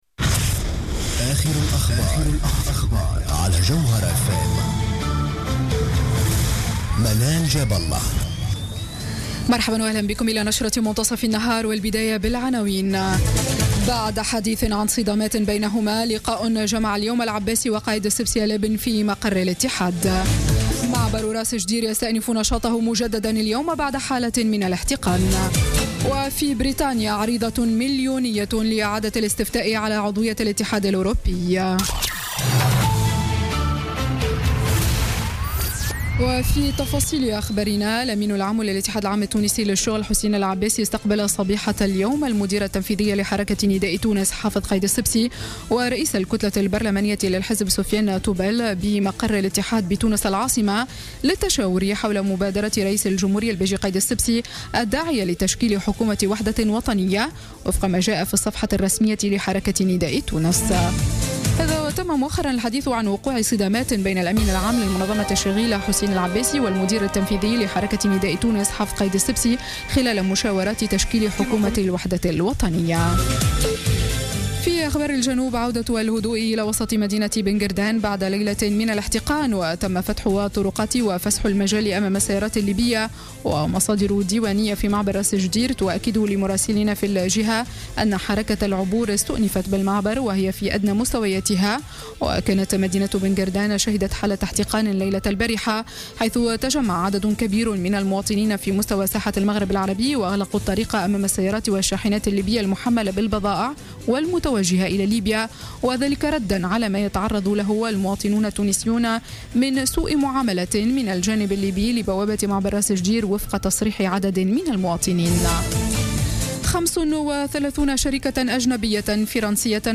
نشرة أخبار منتصف النهار ليوم السبت 25 جوان 2016